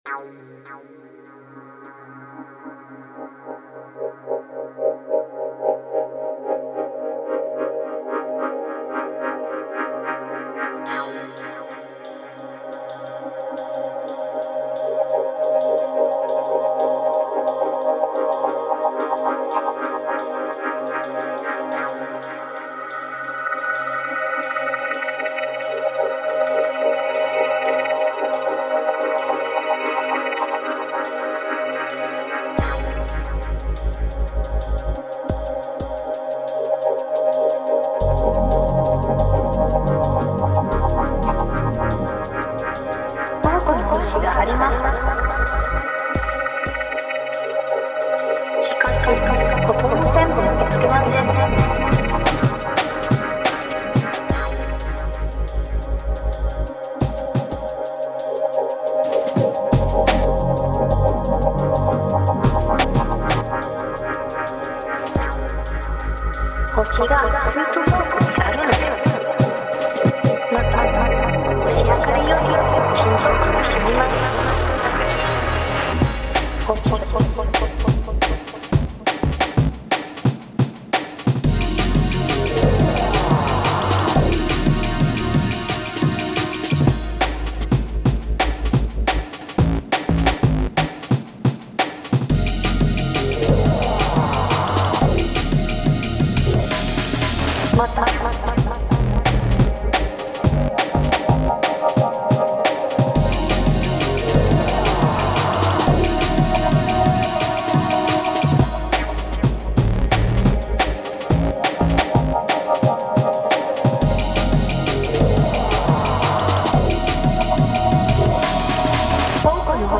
Moderner Drum'n'Bass ?